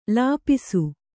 ♪ lāpisu